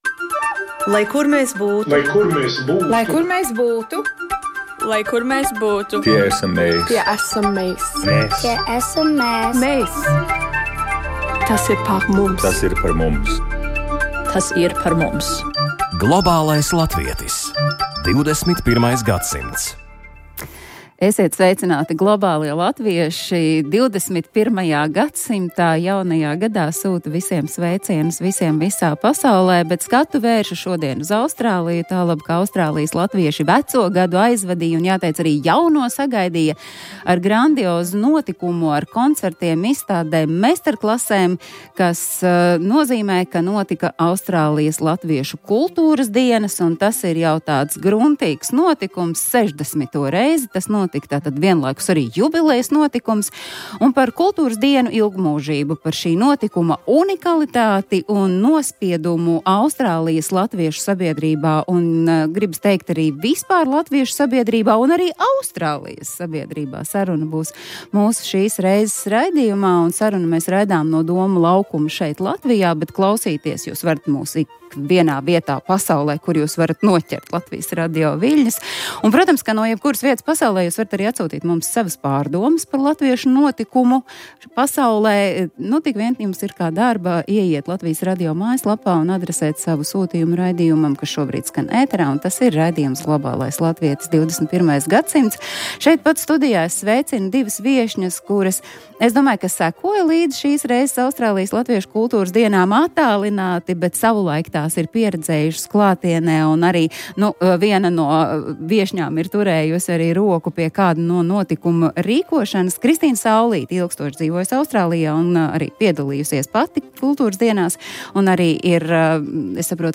Austrālijas latvieši Jauno gadu sagaidīja ar grandiozu notikumu - koncertiem, izstādēm un meistarklasēm jeb Austrālijas latviešu kultūras dienām, kas notika jau 60. reizi. Par kultūras dienu ilgmūžību, notikuma unikalitāti un nospiedumu Austrālijas latviešu sabiedrībā saruna raidījumā Globālais latvietis. 21. gadsimts.